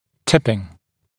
[‘tɪpɪŋ][‘типин]типпинг, наклон; отклонение, искривление, отклонение от вертикальной оси, наклон коронки зуба